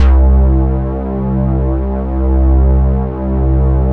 Arp Bass.wav